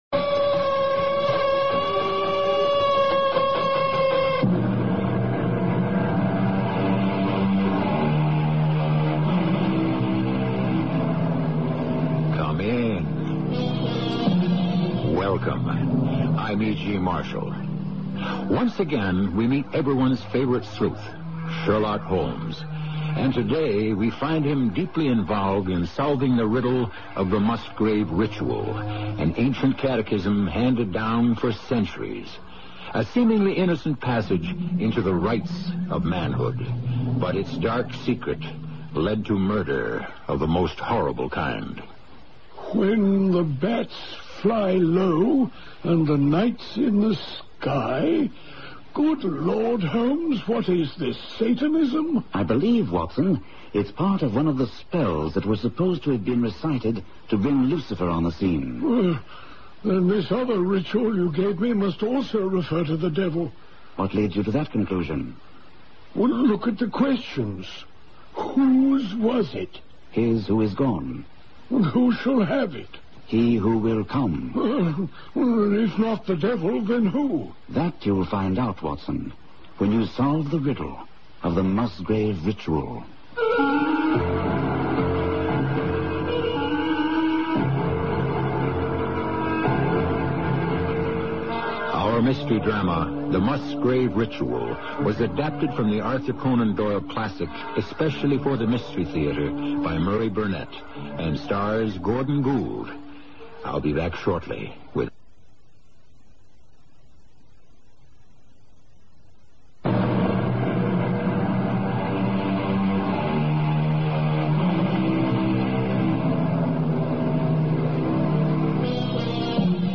Radio Show Drama with Sherlock Holmes - The Musgrave Ritual 1981